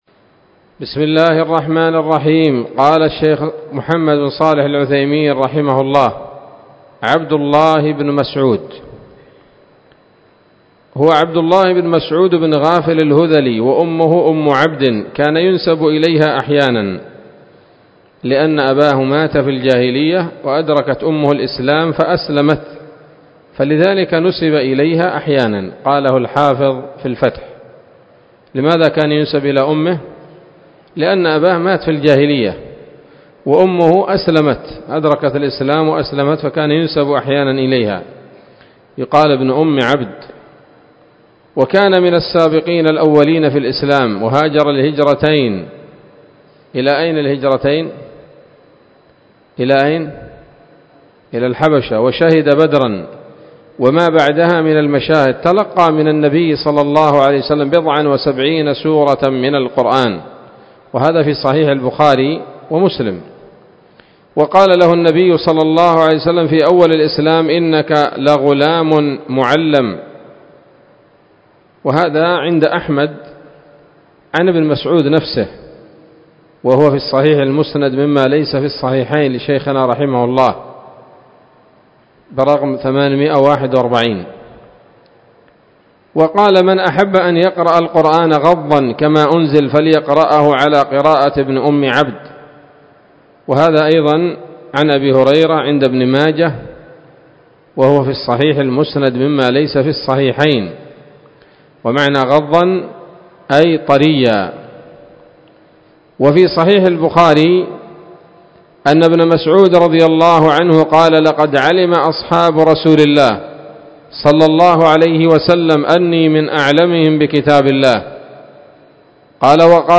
الدرس الخامس والعشرون من أصول في التفسير للعلامة العثيمين رحمه الله تعالى 1446 هـ